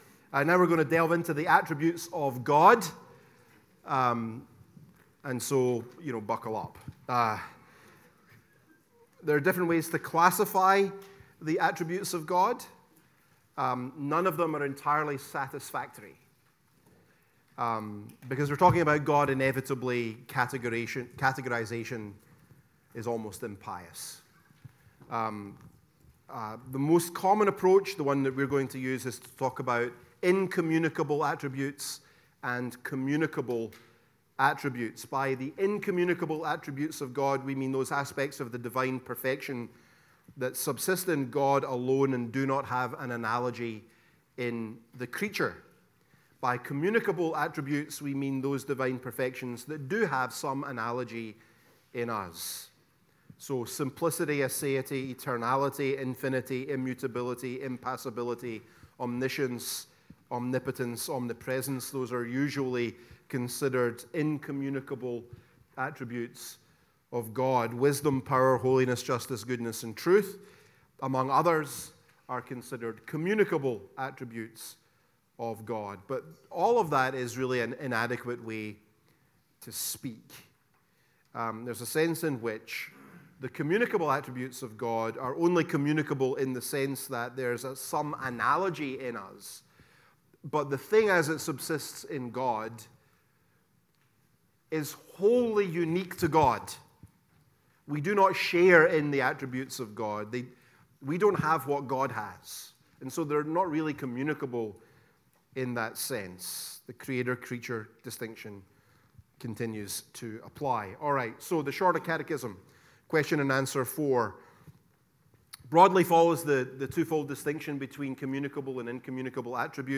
The-Doctrine-of-God-Lecture-2-The-Attributes-of-God-Part-1.mp3